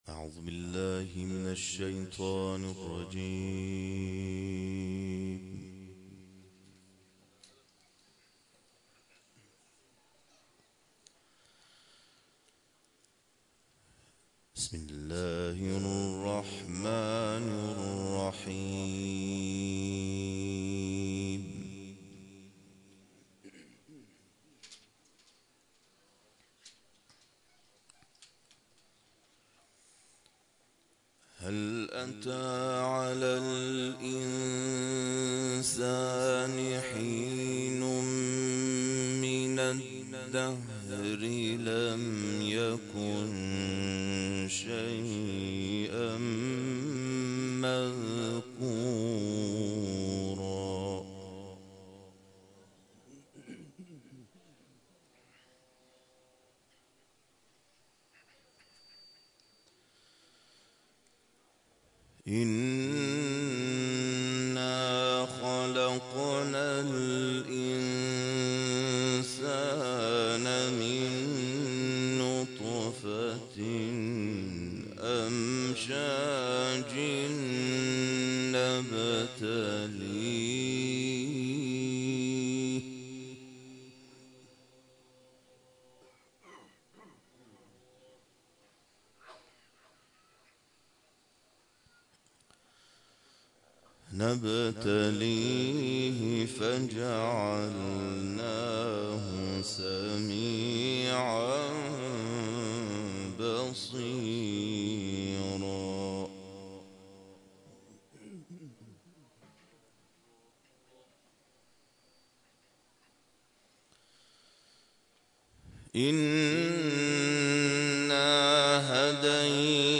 این قاری خوش‌خوان کشورمان در این محفل قرآنی، آیاتی از سوره انسان را تلاوت کرد که صوت تلاوت او قابل شنیدن و دریافت از پیوست خبر است.